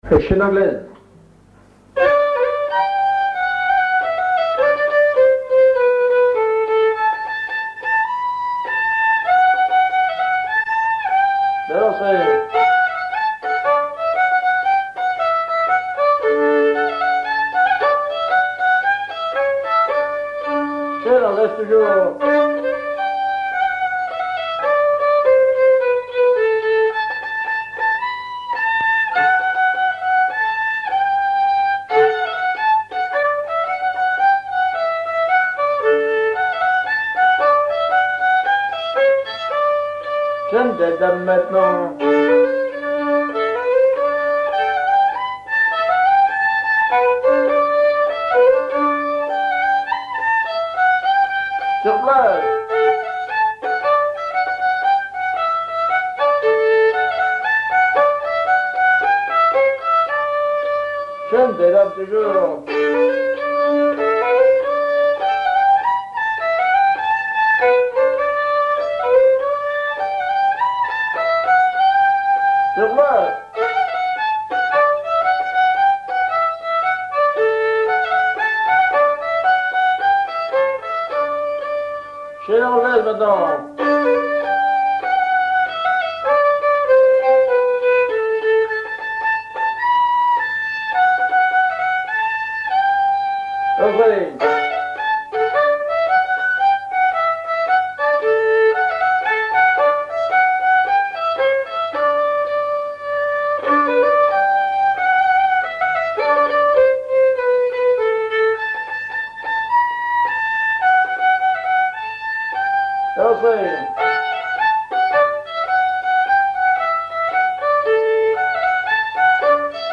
danse : quadrille : chaîne anglaise
Auto-enregistrement
Pièce musicale inédite